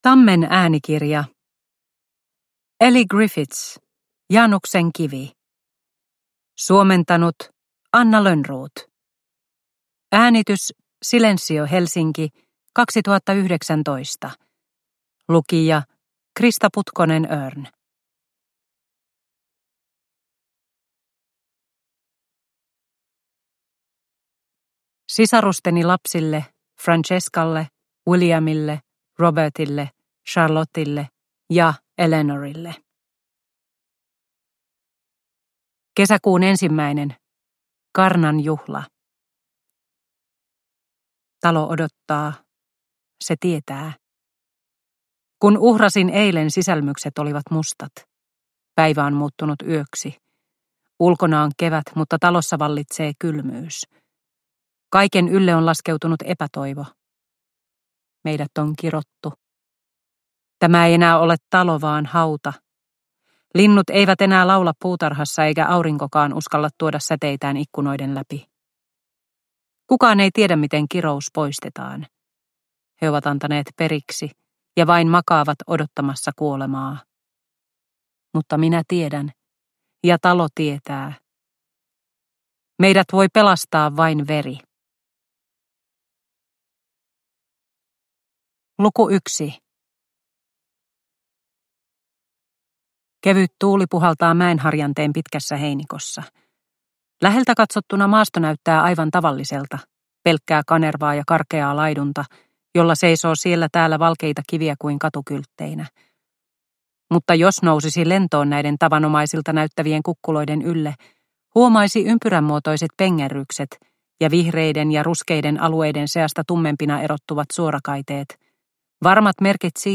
Januksen kivi – Ljudbok – Laddas ner